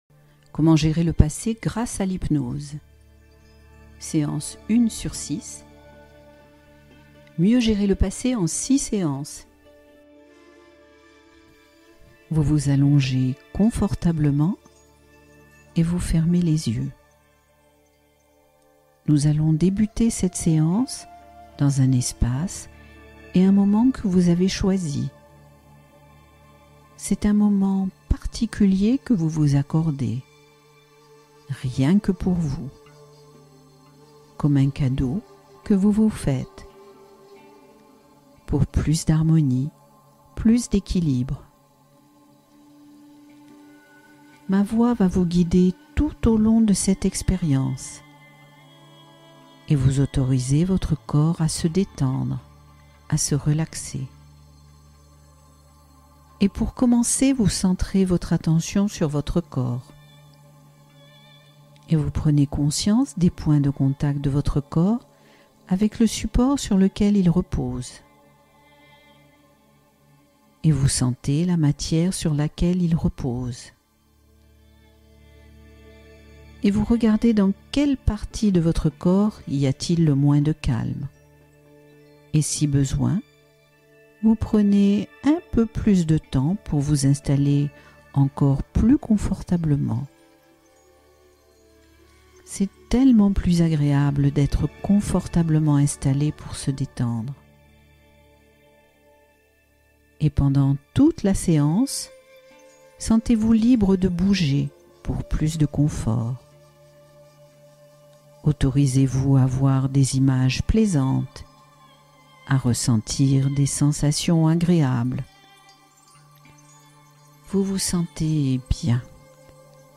Mémorisation amplifiée — Hypnose ciblée pour apprendre plus facilement